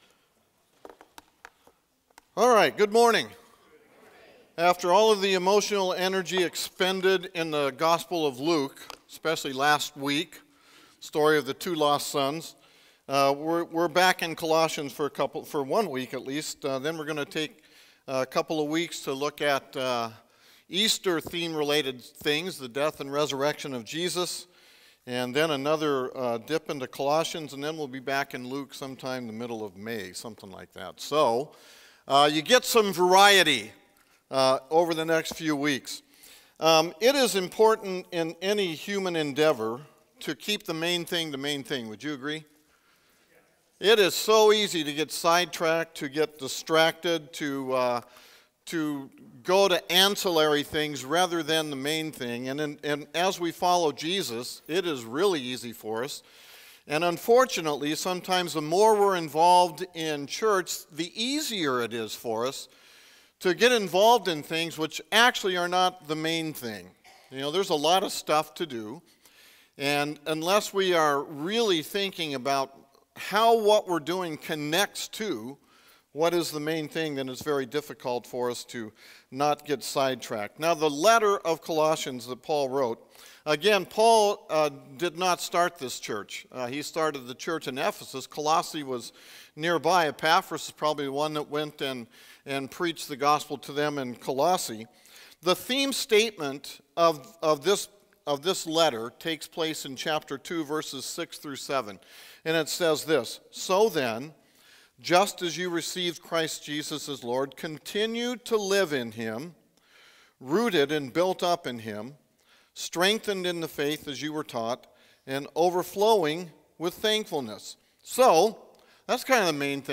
Sermons | Magnolia Baptist Church